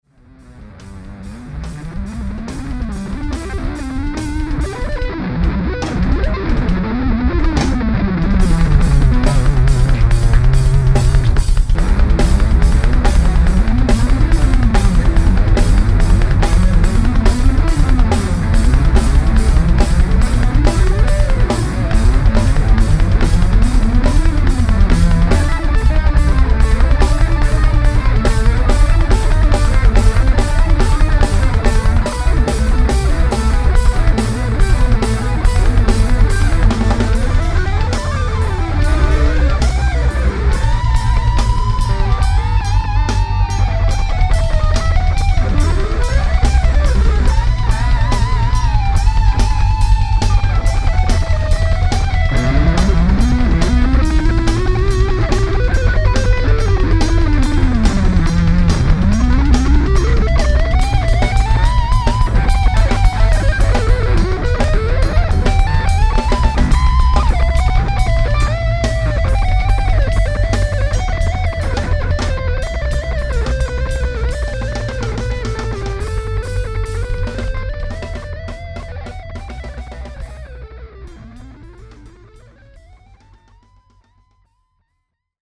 :aka "zware muizen".Now if this doesn't grab your attention...Attention,for guitarfreaks only (don't say I didn't warn you)